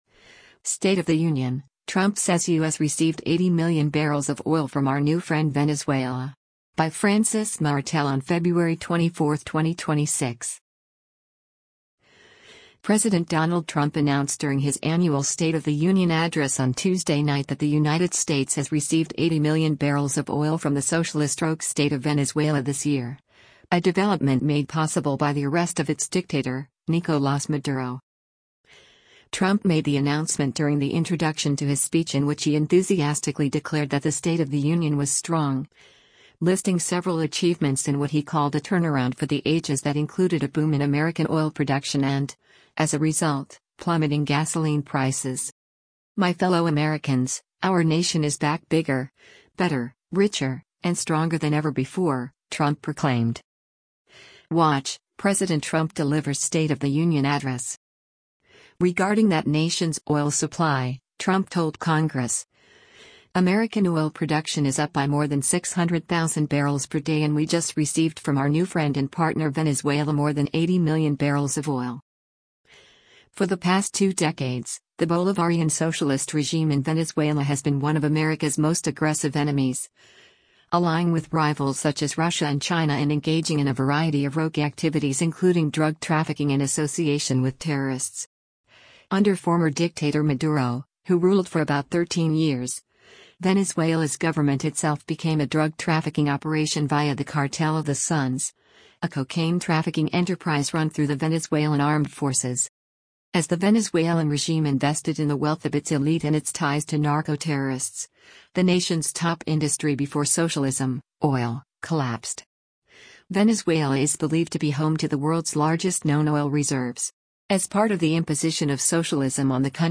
U.S. President Donald Trump delivers the State of the Union address during a joint session
WATCH — President Trump Delivers State of the Union Address: